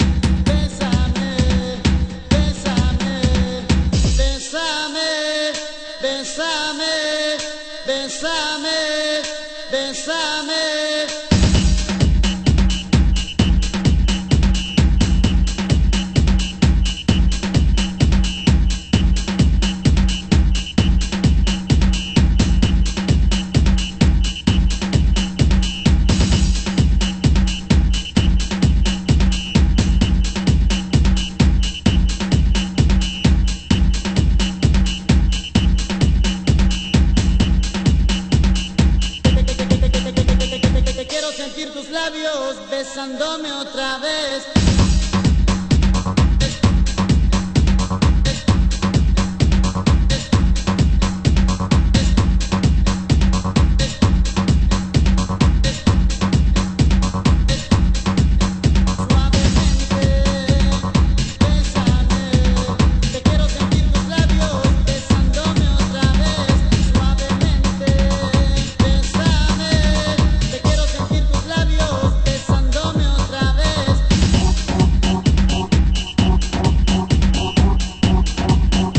At Copa Mix) 　　　盤質：少しチリノイズ有